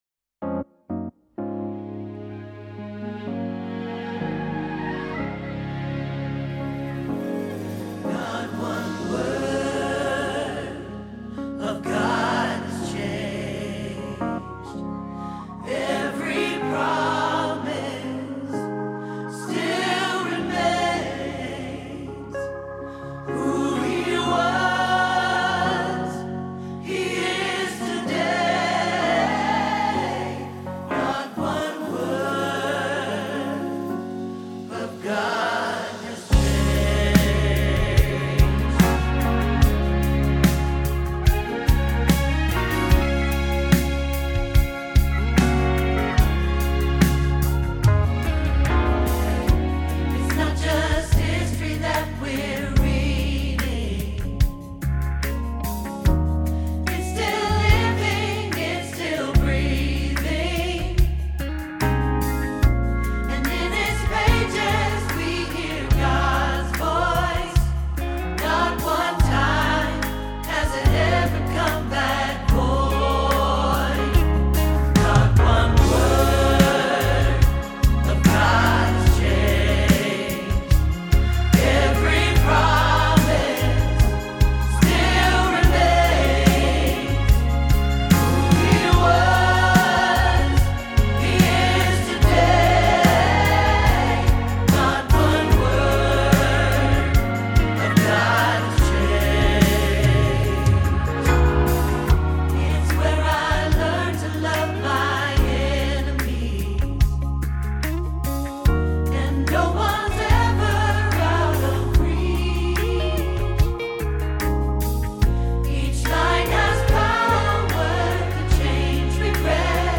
Not One Word – Alto – Hilltop Choir